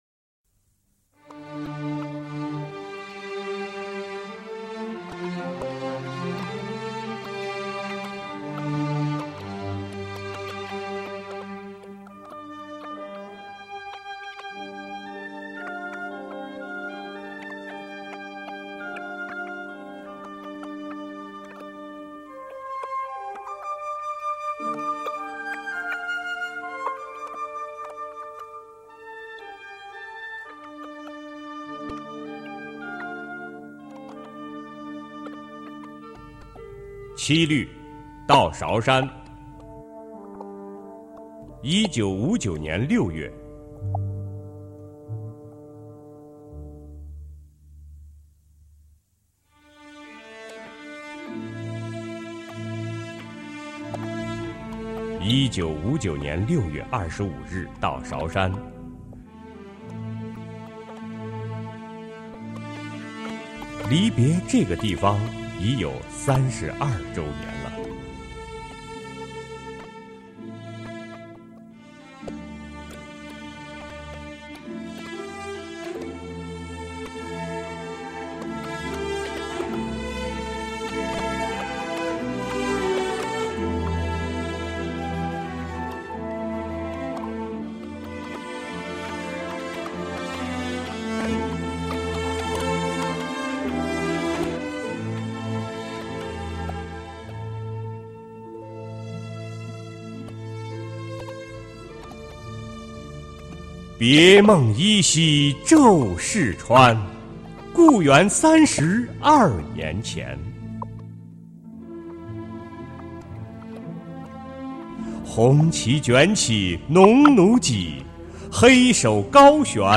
首页 视听 经典朗诵欣赏 毛泽东：崇高优美、超越奇美、豪华精美、风格绝殊